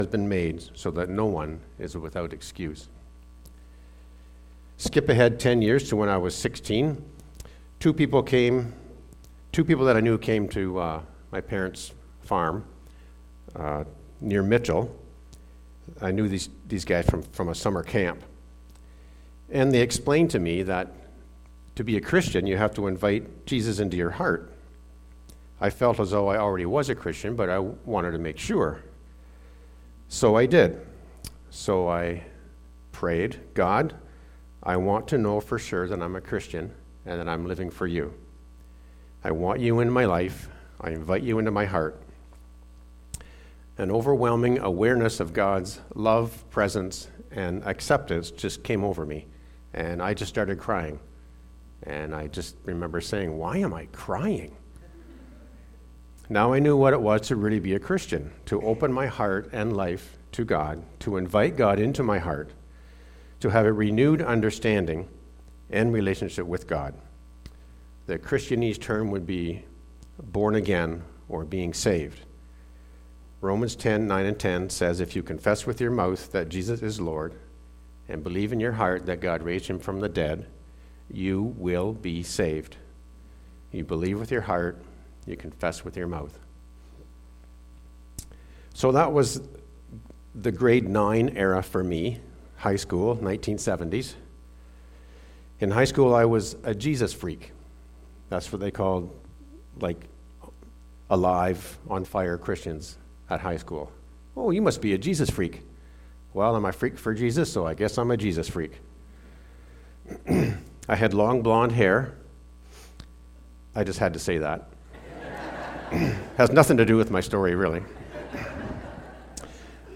Testimonies by CBC